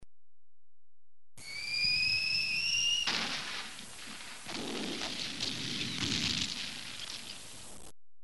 دانلود صدای بمب و موشک 11 از ساعد نیوز با لینک مستقیم و کیفیت بالا
جلوه های صوتی